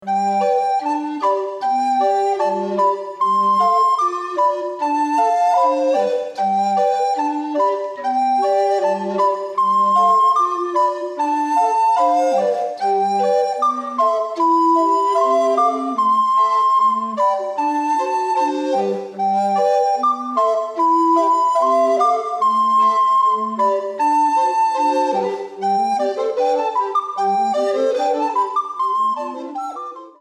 S A T B